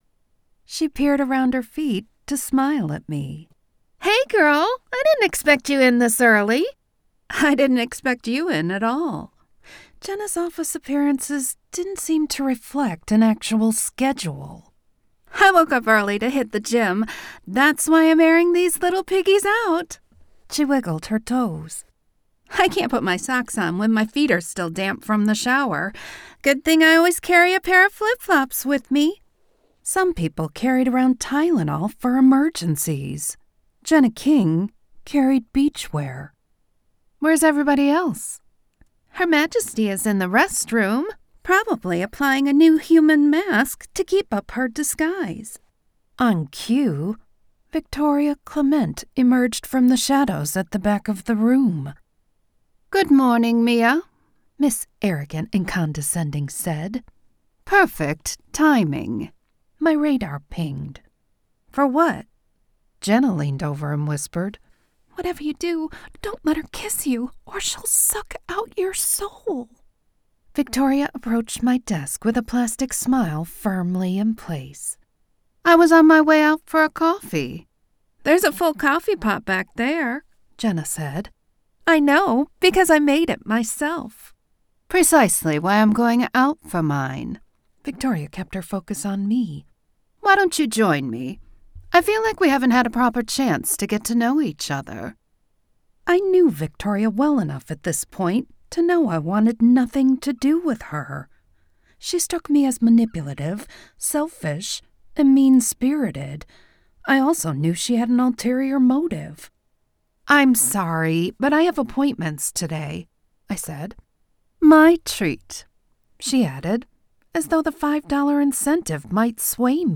Narrator
Accent Capabilities: Full narrative: American, Midwest, and Southern
Secondary characters: English/British, Canadian, Russian, Middle Eastern, Hispanic, Asian and Irish.